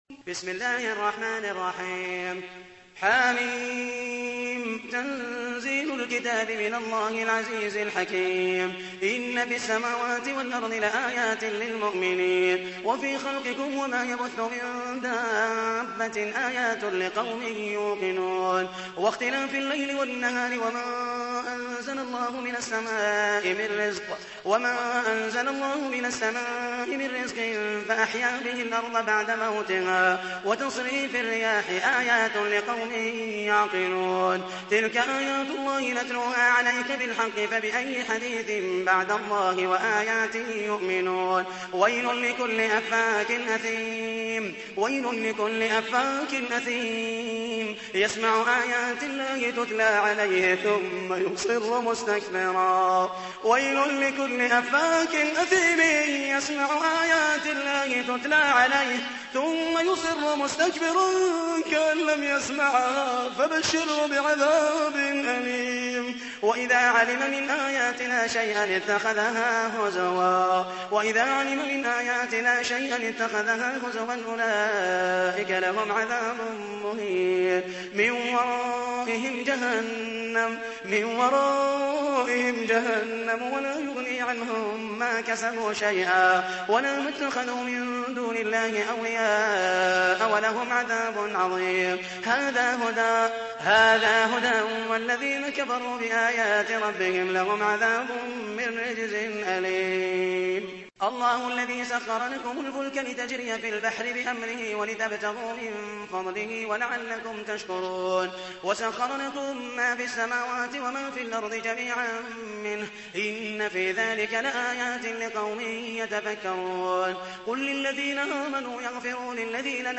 تحميل : 45. سورة الجاثية / القارئ محمد المحيسني / القرآن الكريم / موقع يا حسين